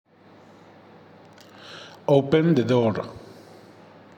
Notebooks a entregar: Enlace EX1 Representación texto EX2 Clasificador de preguntas EX2.5 NLTK y Python EX3 Analisis de Sentimientos IMDB EX4 Asistente virtual WAV con el comando de ejemplo "Open the door" (Para EX4)
OpenTheDoor.wav